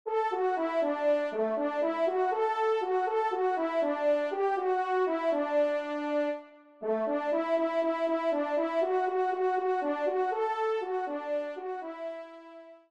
Trompe (Solo, Ton simple)